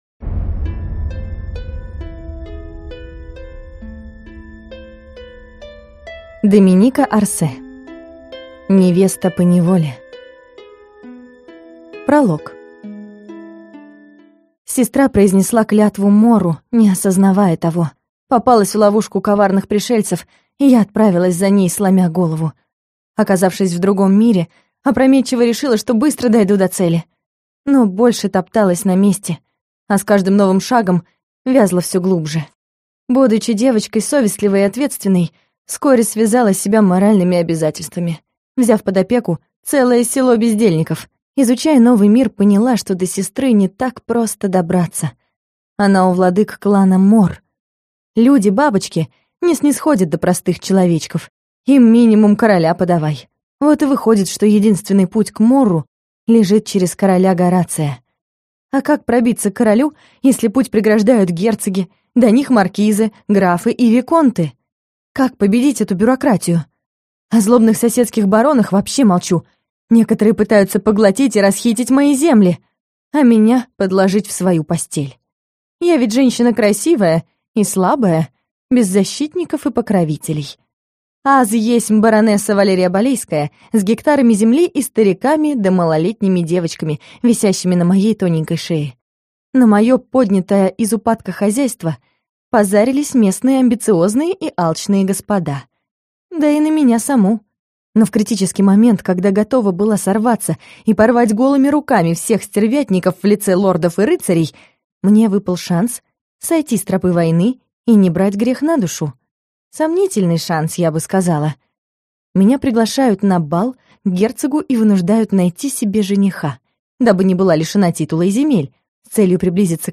Аудиокнига Невеста поневоле | Библиотека аудиокниг